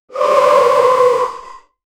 violent-distorted-scream--rdaxqqjo.wav